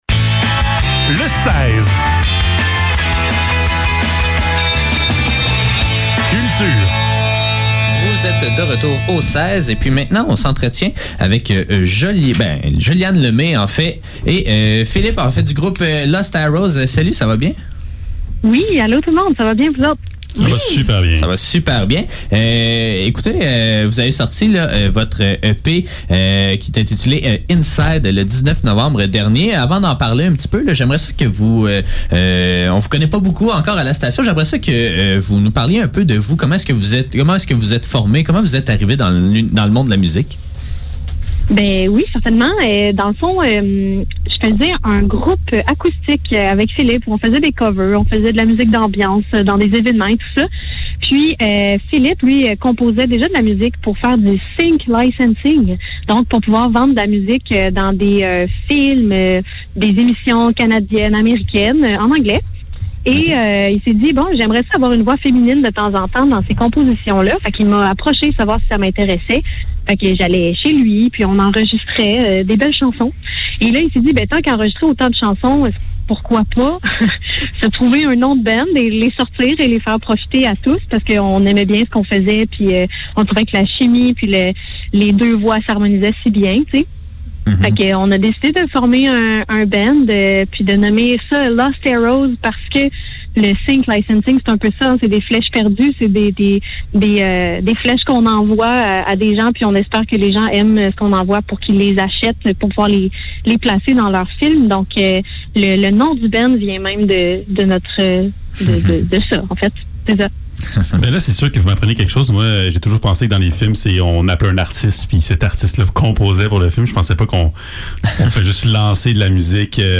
Entrevue-avec-Lost-Arrows.mp3